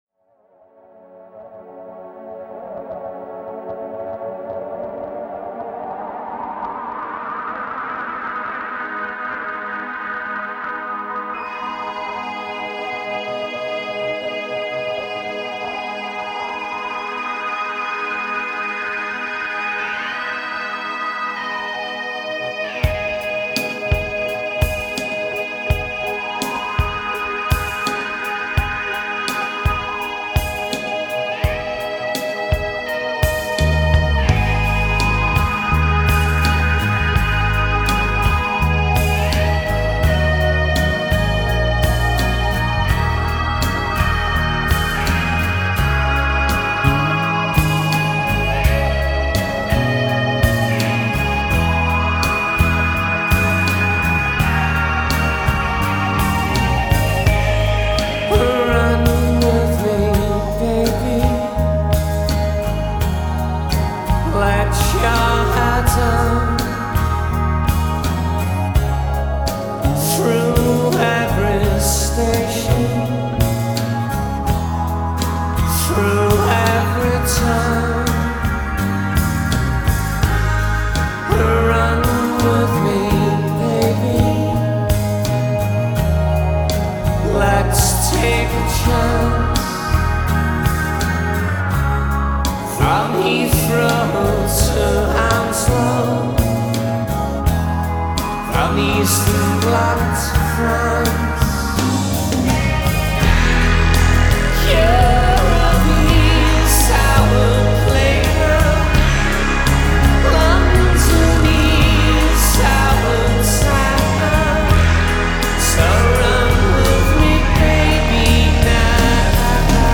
Style: Alt Rock